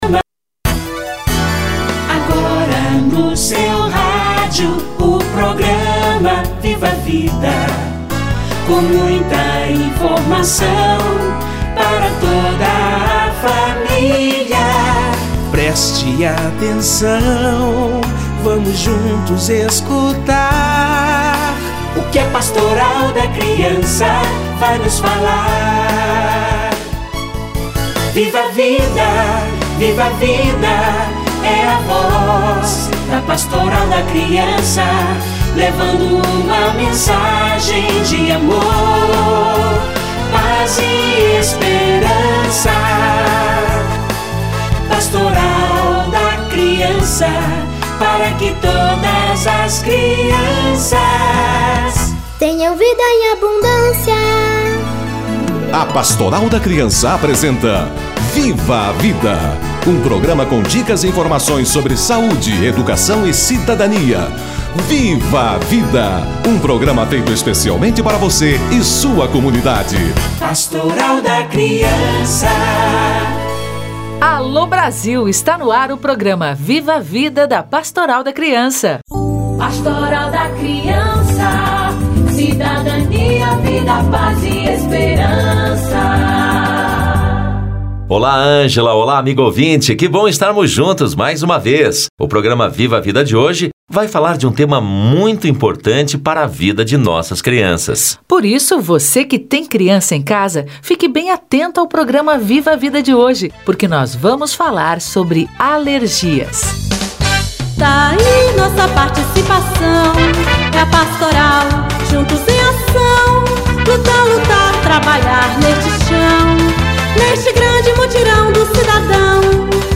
Alergia nas crianças - Entrevista